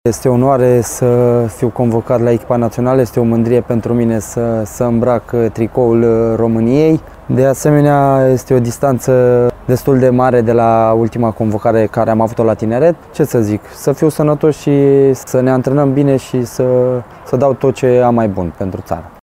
au oferit declarații la FRF TV